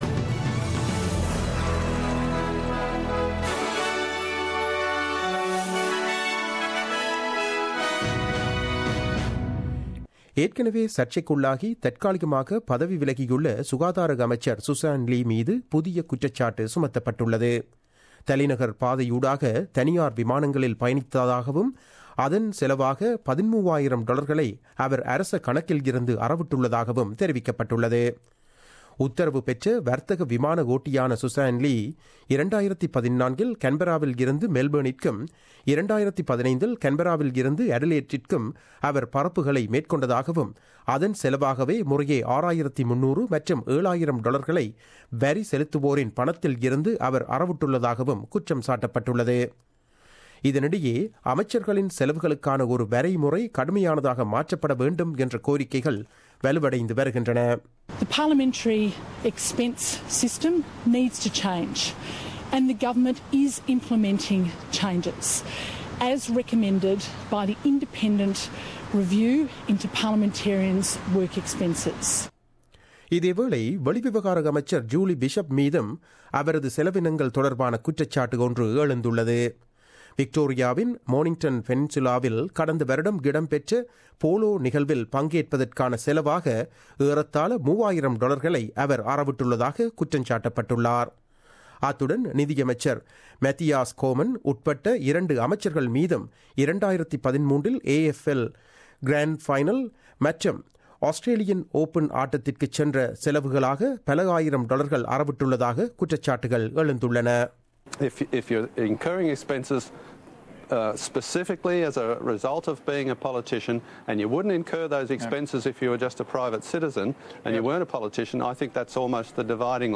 The news bulletin aired on 11 January 2017 at 8pm.